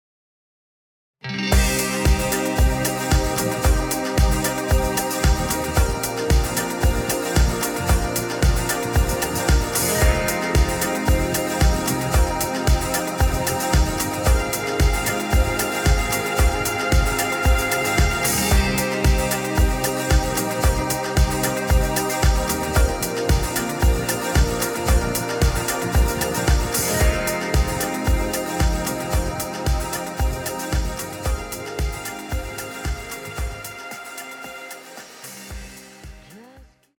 ach das panning könnte man auch noch etwas groszügiger gestalten ;) hast du denn woanders kompression außer auf dem bass? auf bussen irgendwo? oder ist das was man hört die lautstärkeautomation? bzw was macht die automation bei dir genau?